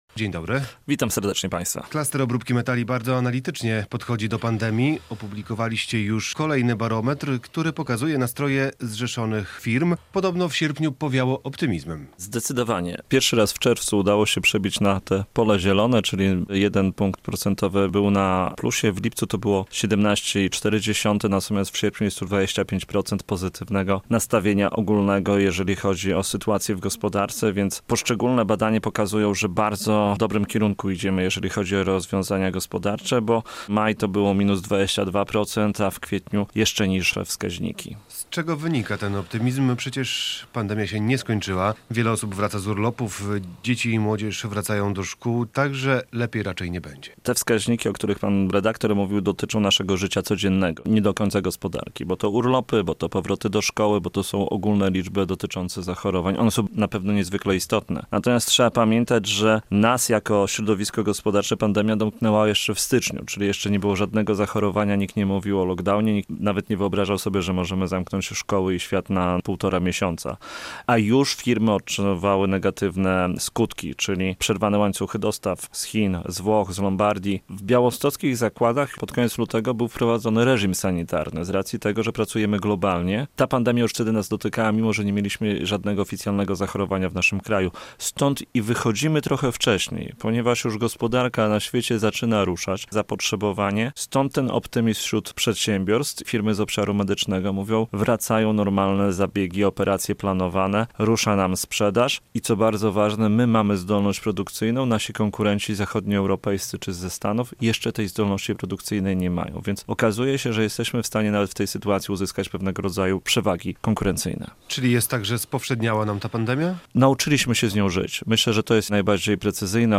studio